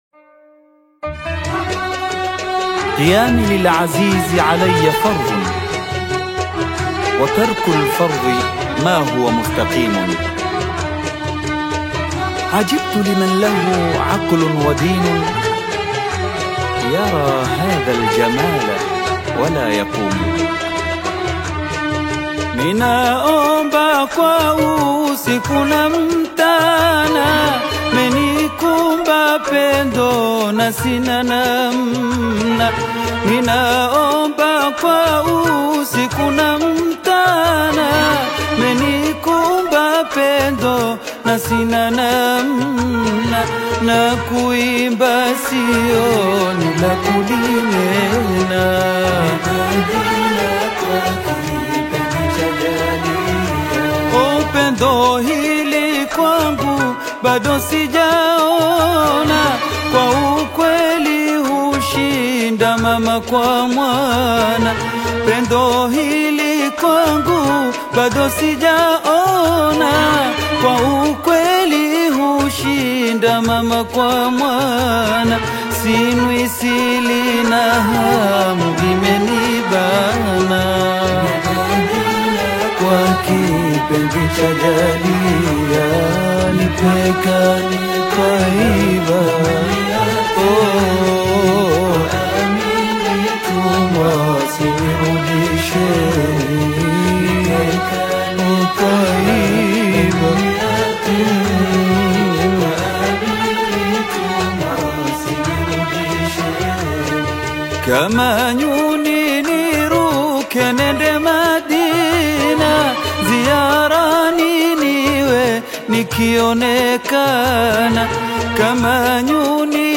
QASWIDA